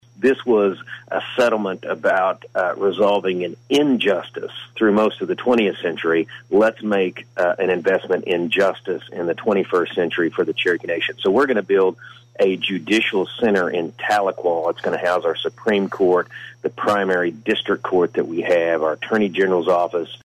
Appearing on KWON's COMMUNITY CONNECTION, Cherokee Nation Principal Chief Chuck Hoskin Jr. announced that